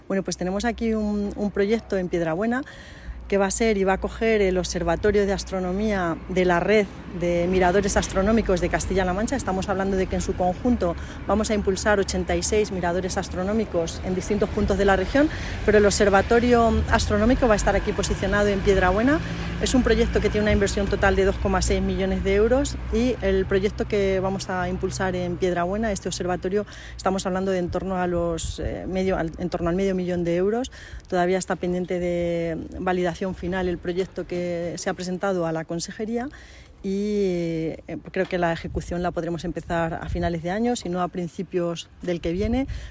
patricia_franco_observatorio_astronomico_piedrabuena.mp3